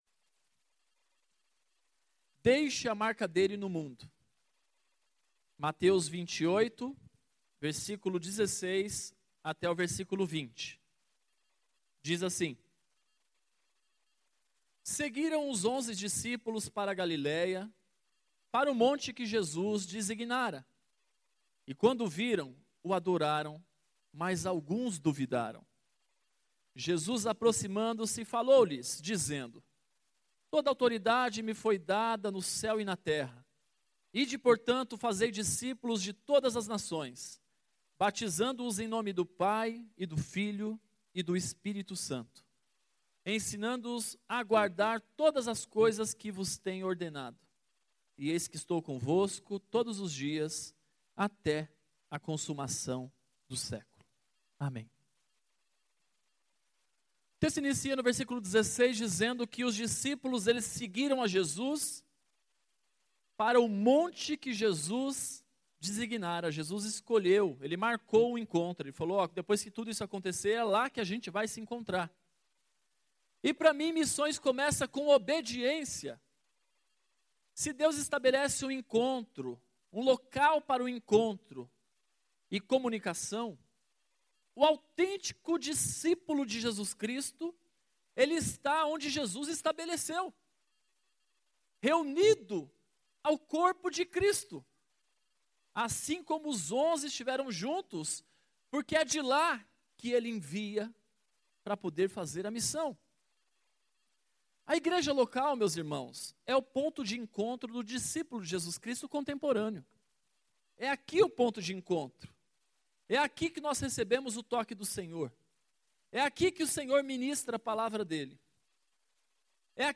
Mensagem da Abertura da Conferência Missionária 2019 no dia 01 de Setembro.